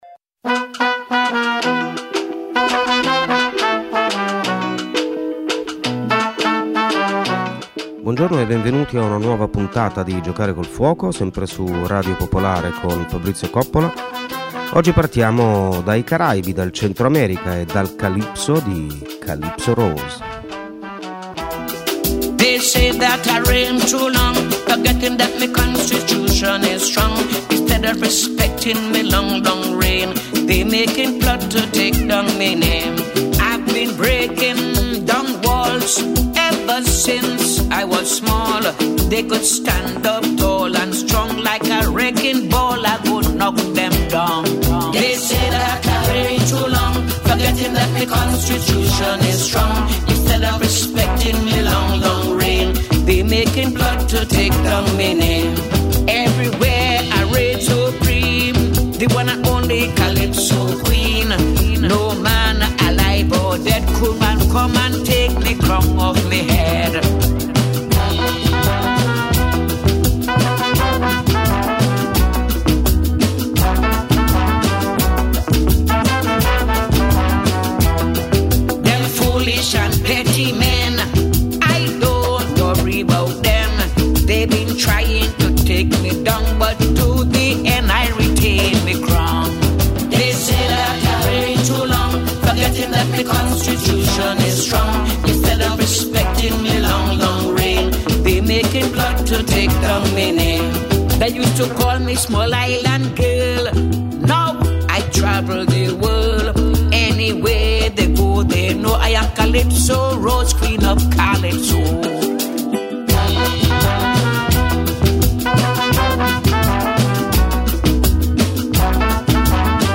Letture